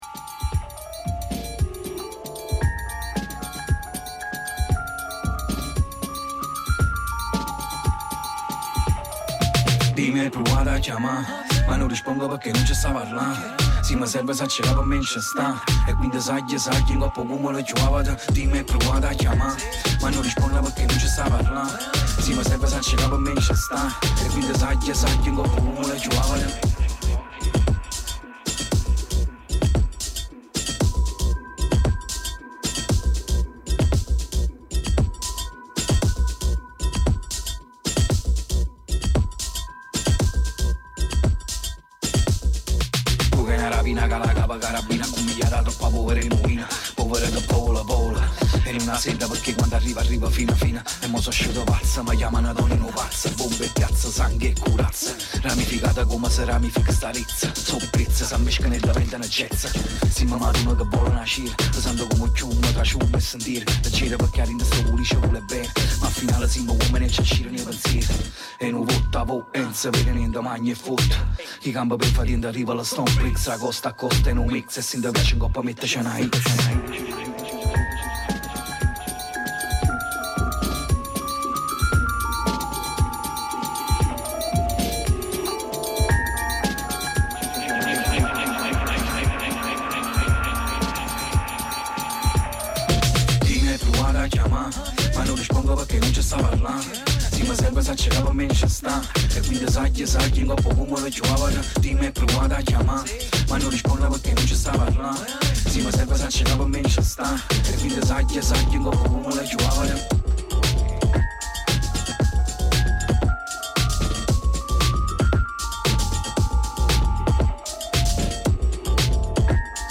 La super ospite ci ha fatto sentire anche un suo pezzo live dal titolo S.O.S Colombia.